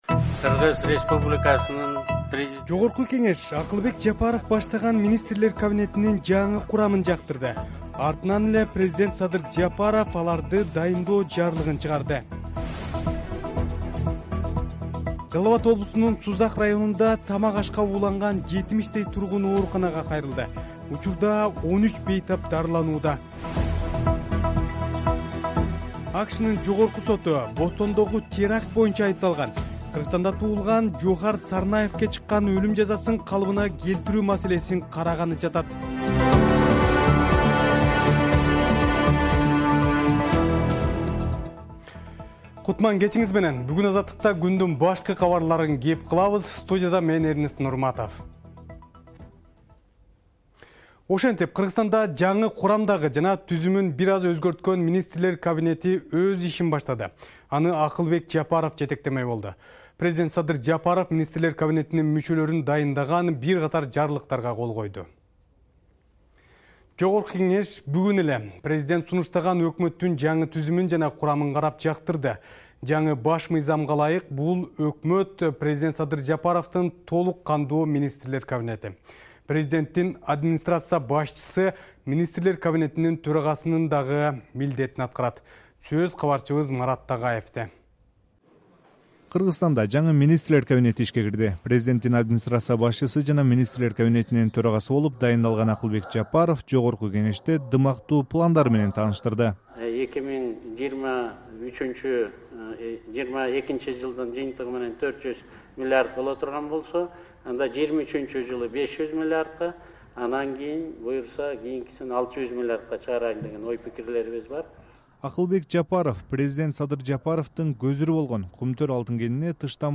Жаңылыктар | 13.10.2021 | Жаңы Министрлер кабинети ишке киришти